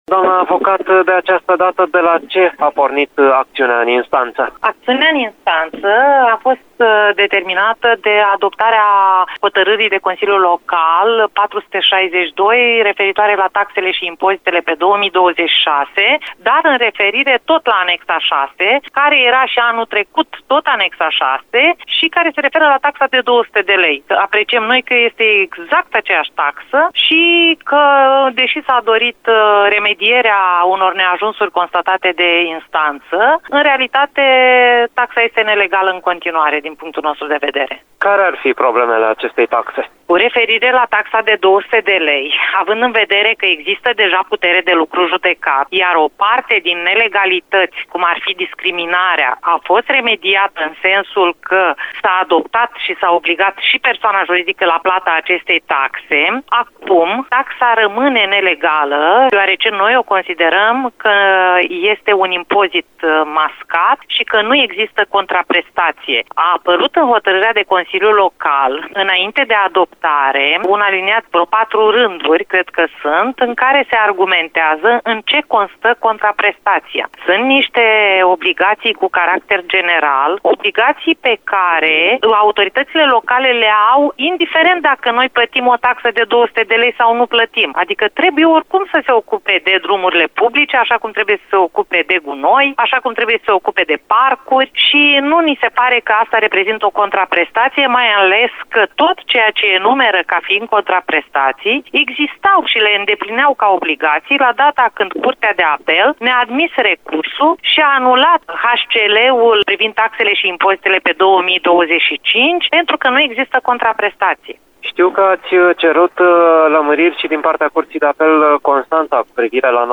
Detalii în interviul